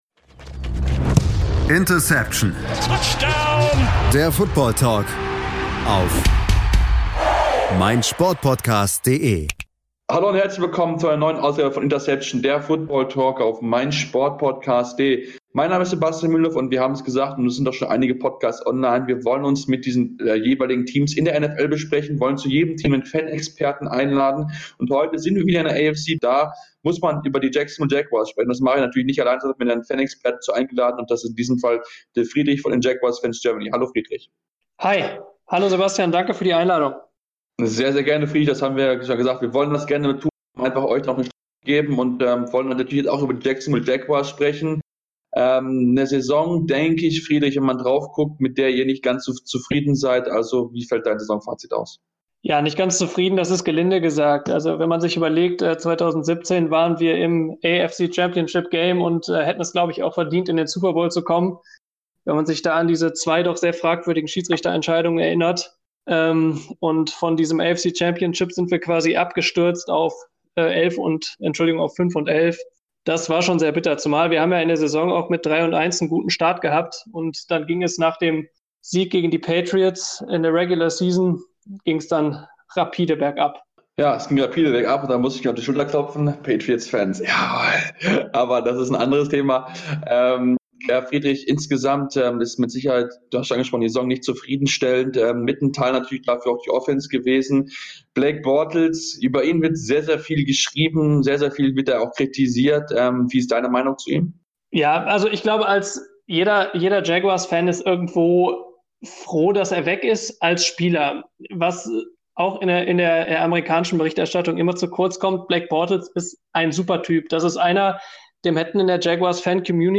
Es gibt eine Ausgabe zu jedem Team in der NFL, in der unsere Crew mit Fan-Experten über die jeweiligen Teams spricht.